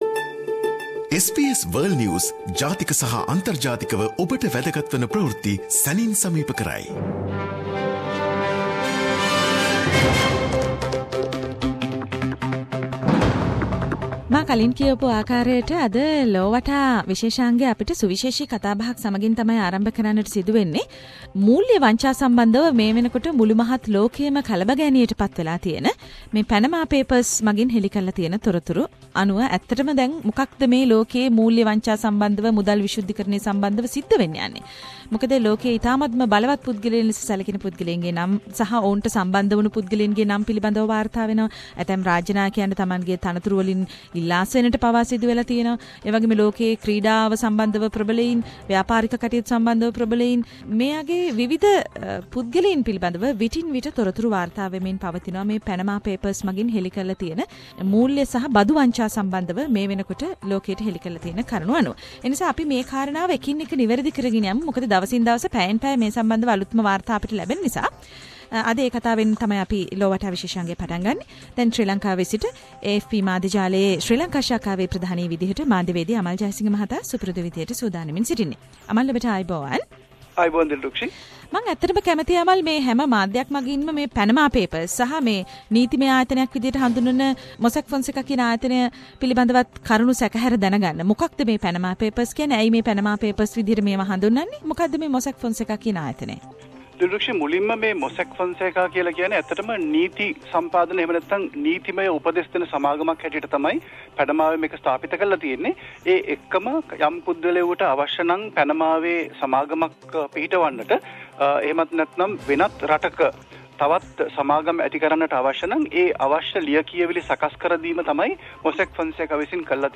SBS Sinhala Around the World - Weekly World News highlights